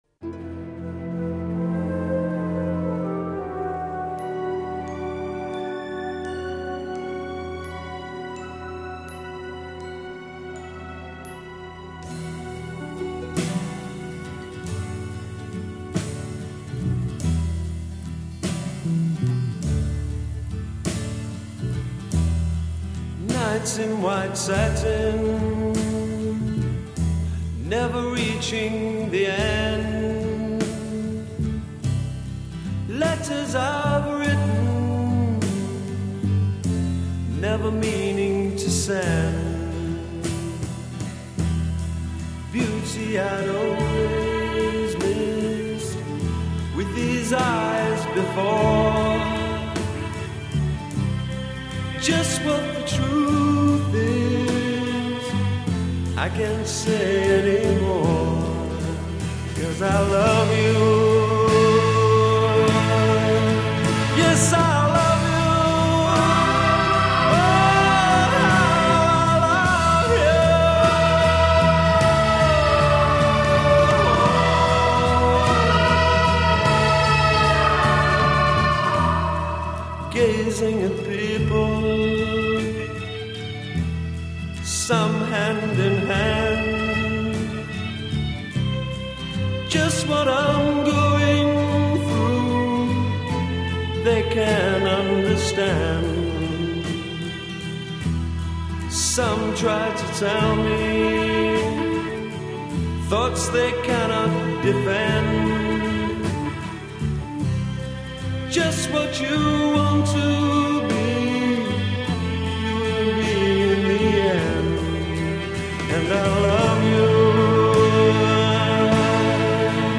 Intro 1   Orchestra with glockenspiel.
Intro 2   Rock ensemble plays quietly.
Verse   Solo voice with heavy reverberation;
Refrain   As above; add wailing chorus. b
Gradually add orchestral instruments.   a
Spoken voice with orchestral coloring to enhance text. d
Orchestral conclusion with tam-tam crash and fade at end.